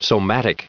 1892_somatic.ogg